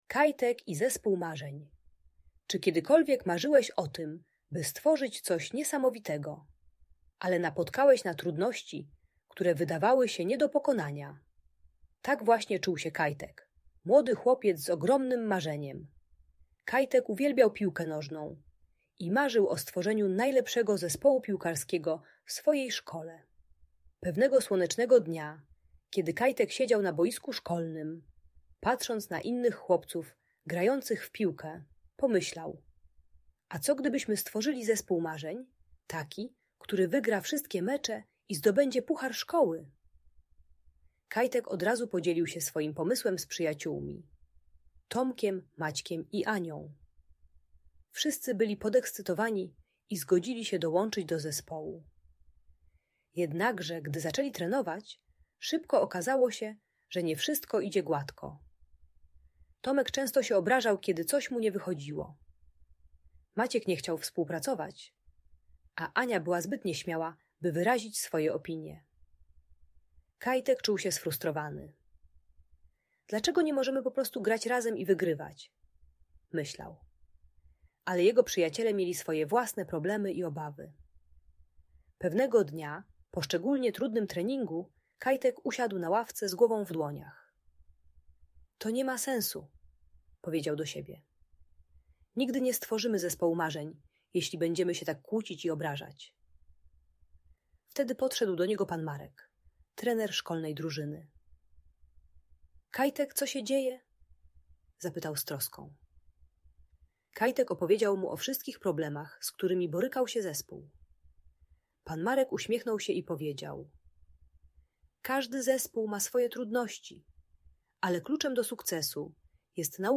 Historia Kajtka i Zespołu Marzeń - Audiobajka